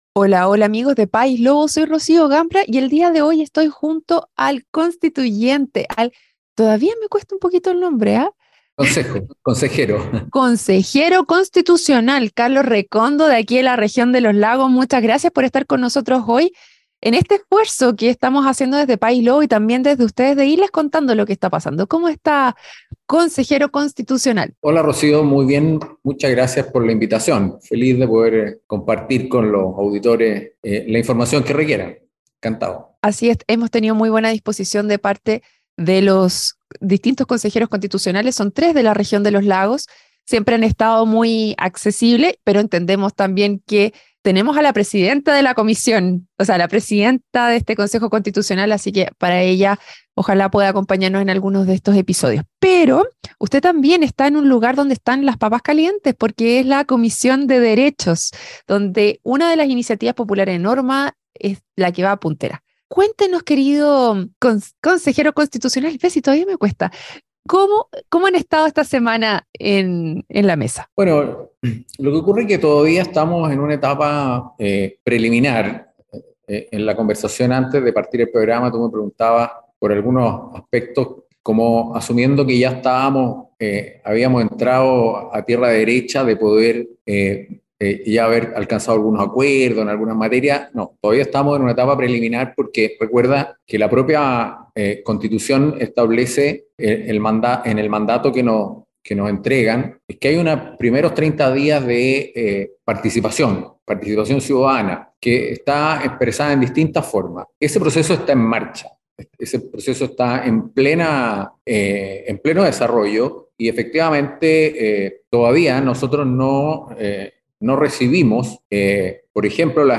En su emisión más reciente, conversó con Carlos Recondo Consejero Constitucional integrante de la comisión de Derechos Económicos, Sociales, Culturales y Ambientales del Consejo Constitucional.